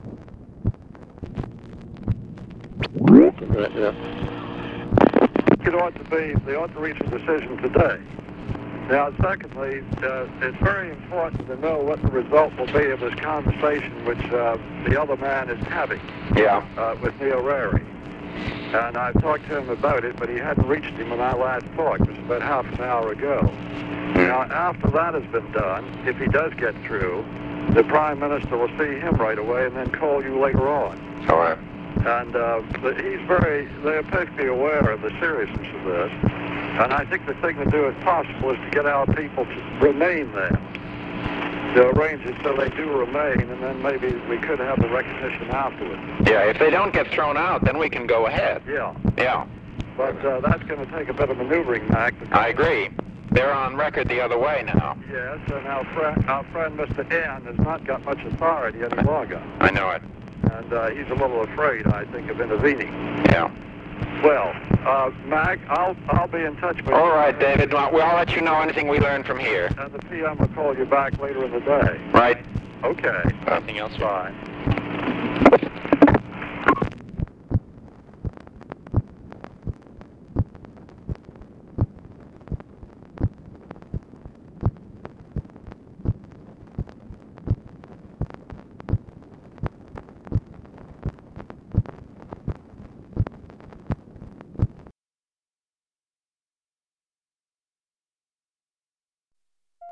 Conversation with MCGEORGE BUNDY, February 19, 1964
Secret White House Tapes